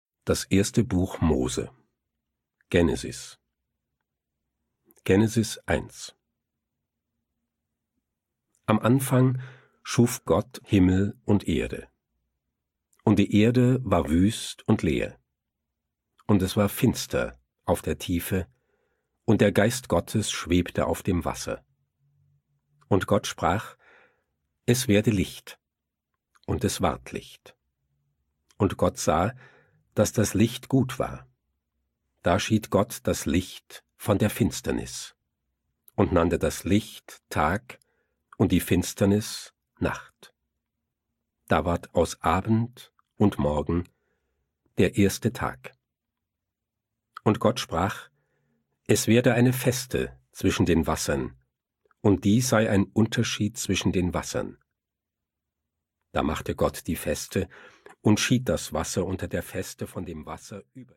Hörbibel Luther 1912 - MP3 - CD